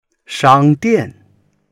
shang1dian4.mp3